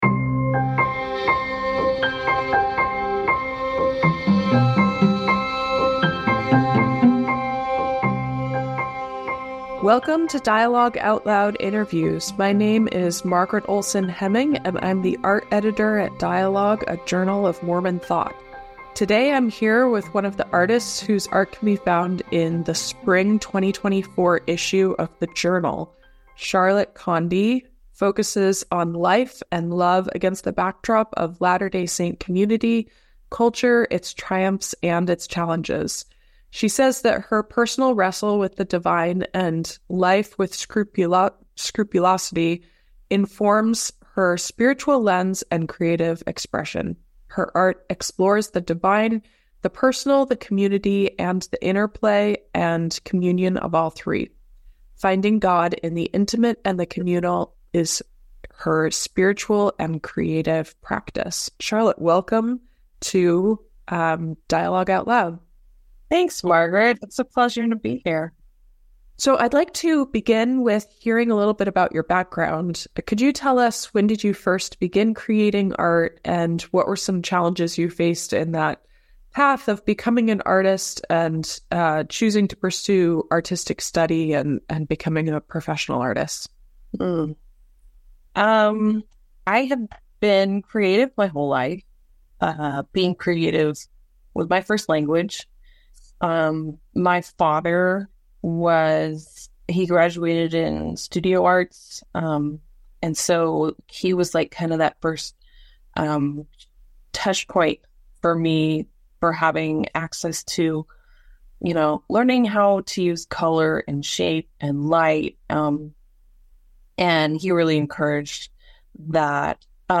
Deconstructing Religion Through Art: A Conversation